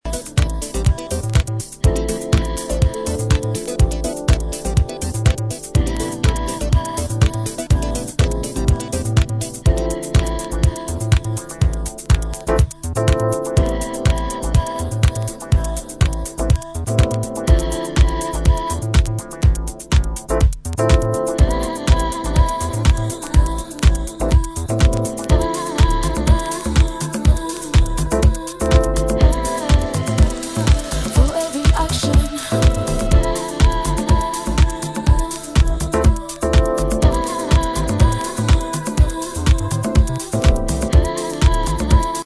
liebliche Stimme
wunderbar deepen Housetunes